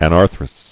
[an·ar·throus]